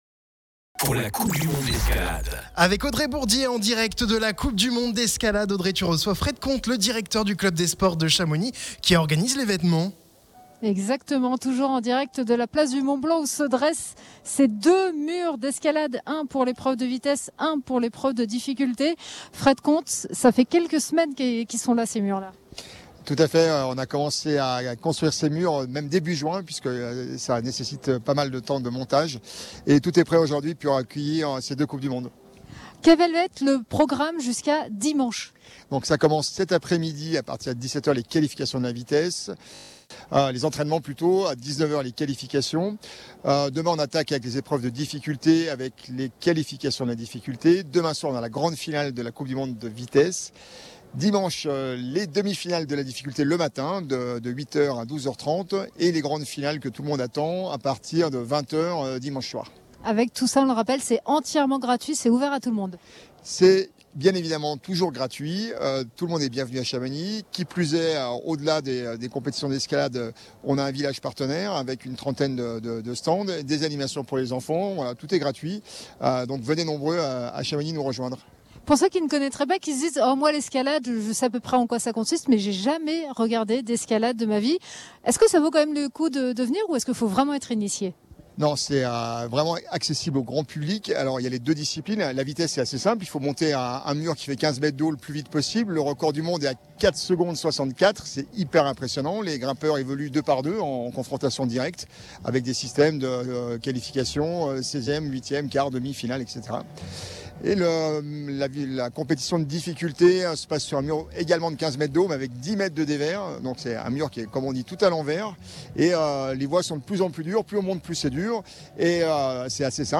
Radio Mont Blanc en direct de la Coupe du Monde d’Escalade à Chamonix !